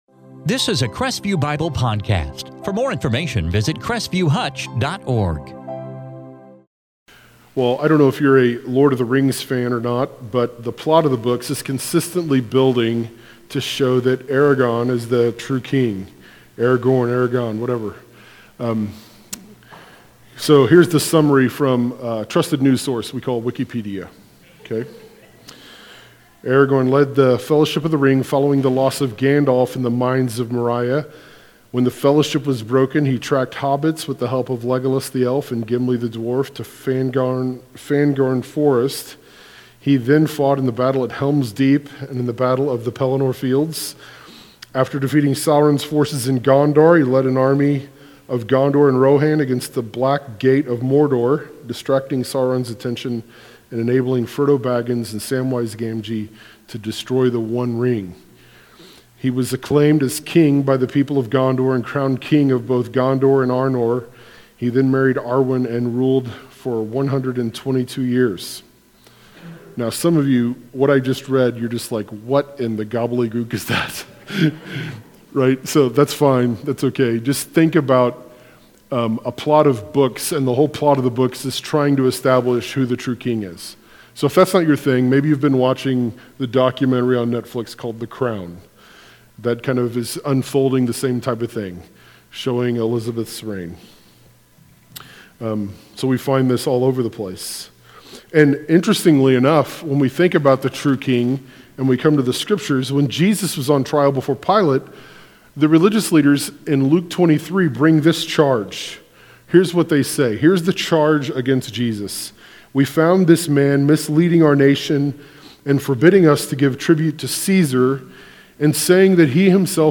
In this sermon from Acts 12